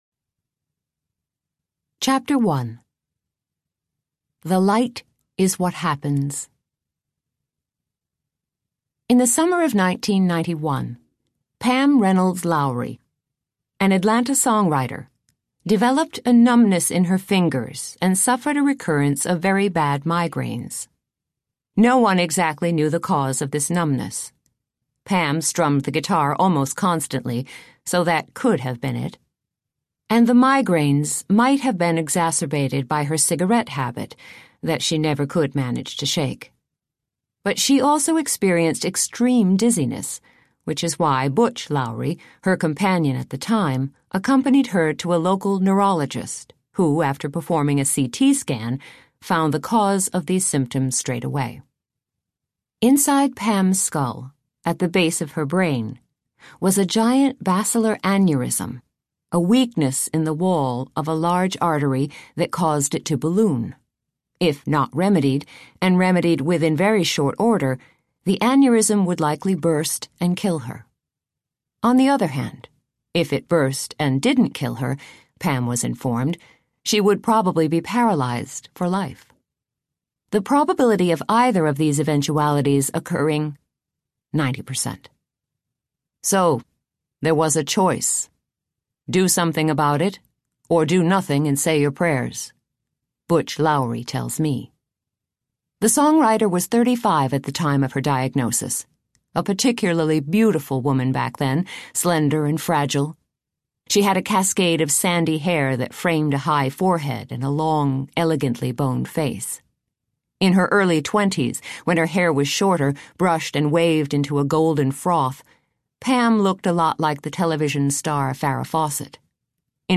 Glimpsing Heaven Audiobook
Narrator